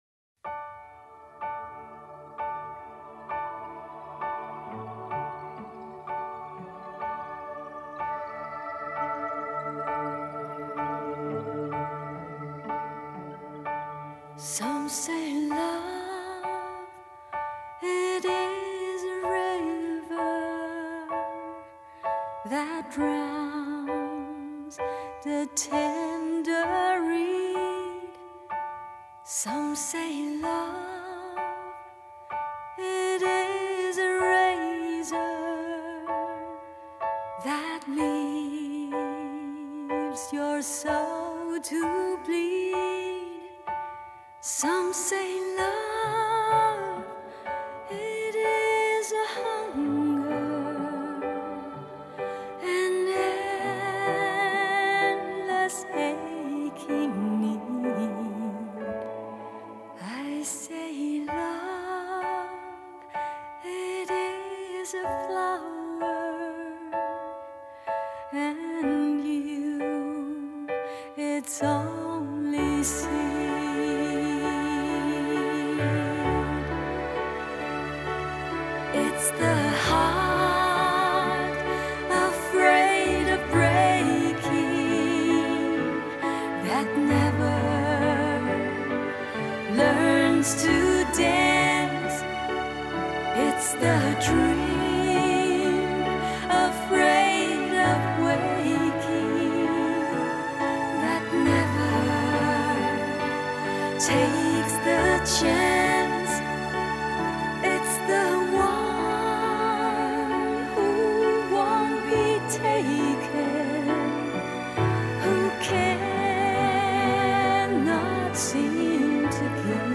☆专业24bit混音母带处理，原音高品质重现
优雅的英文咬字，伴随张力十足的情感拿捏与澎湃感人的唱功，总是让人无法自拔地如痴如醉。